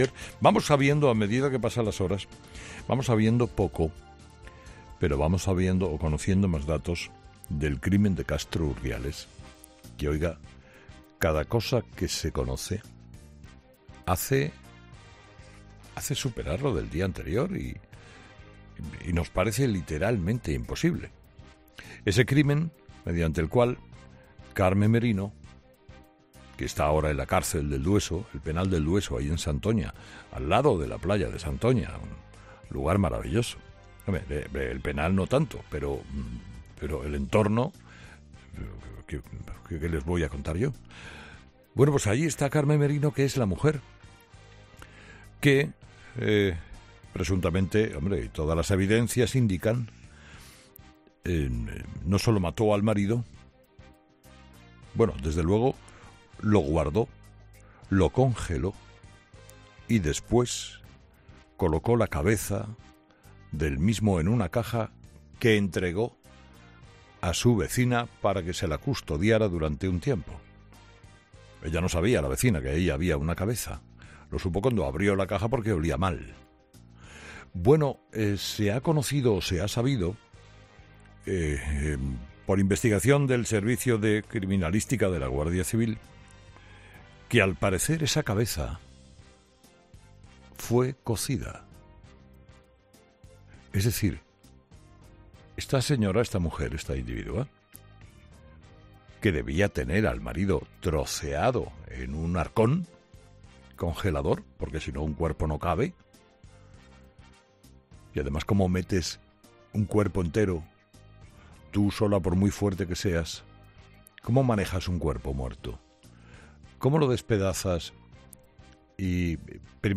Carlos Herrera ha explicado en su monólogo en 'Herrera en COPE' el estremecedor relato de la decapitación de Castro Urdiales que ha conmocionado a España.